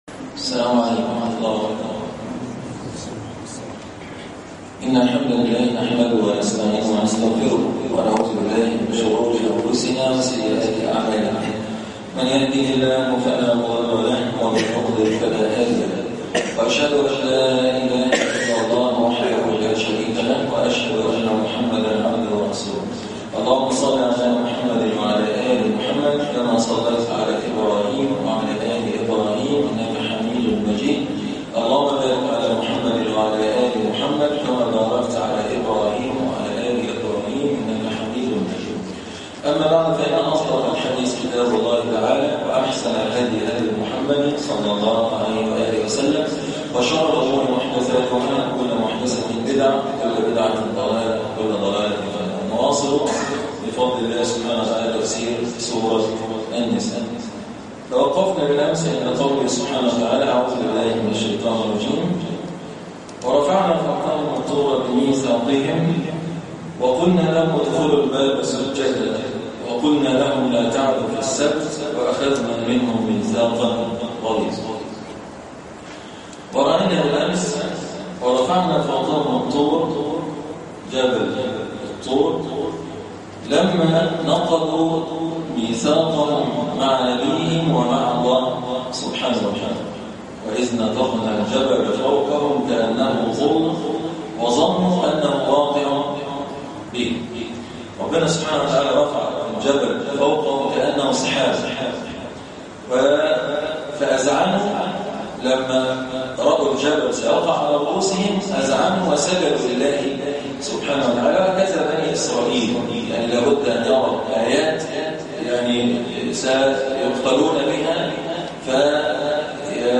الدرس 56- الآيات (155-157) تفسير سورة النساء